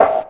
PERC48  03-L.wav